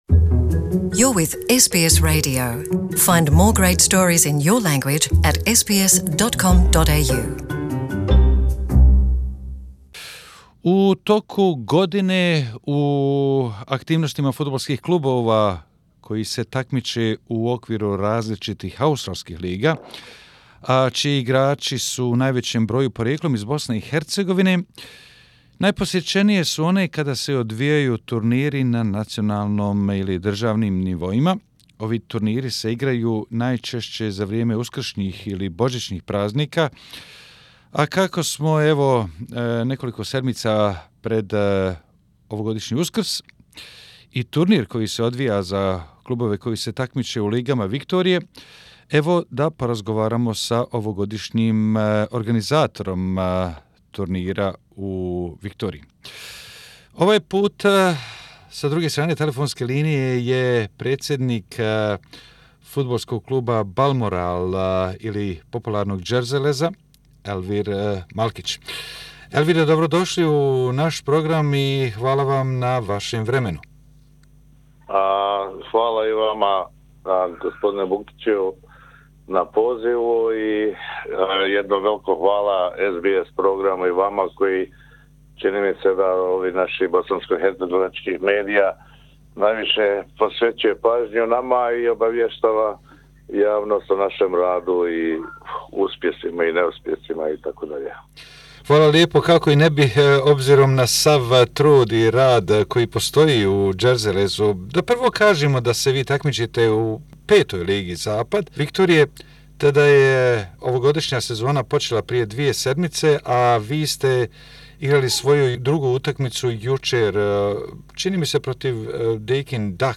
U razgovoru za naš radio program